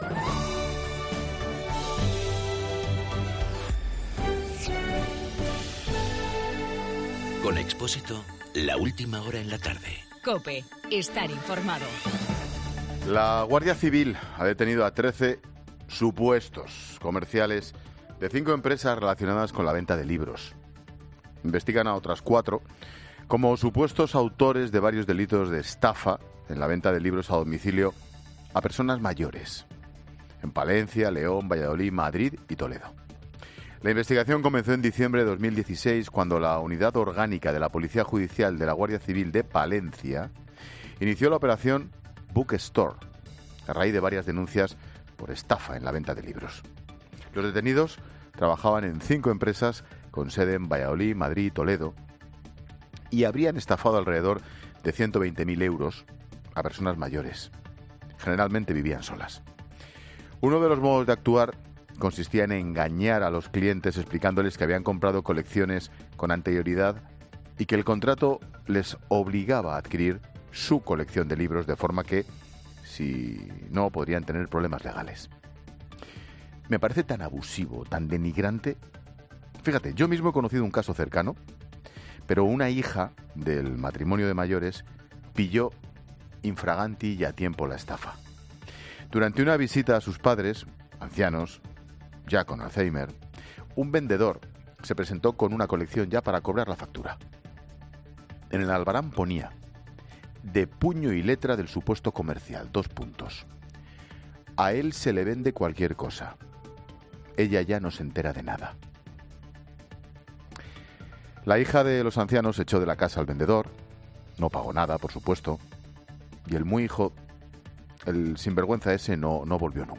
Monólogo de Expósito
El comentario de Ángel Expósito sobre una estafa "repugnante" en Palencia, León, Valladolid, Madrud y Toledo, la operación "Bookstore".